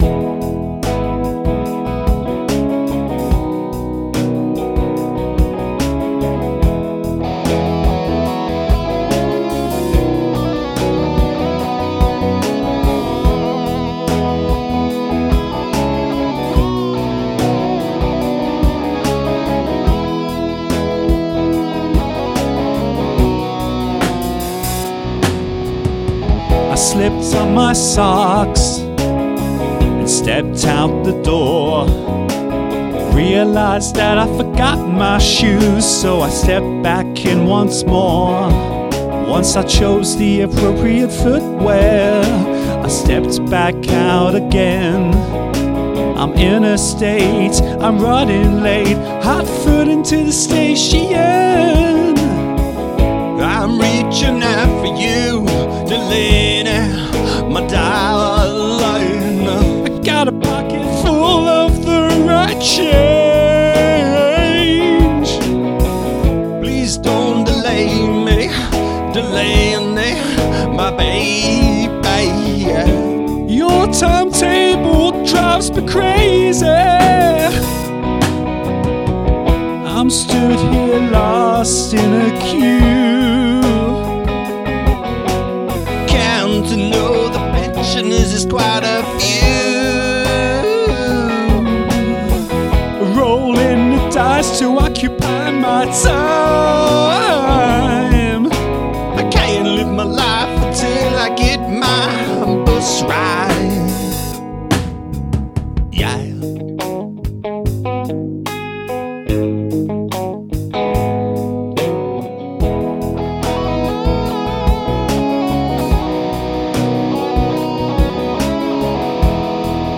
Another joyous romp, a fan favourite, for real.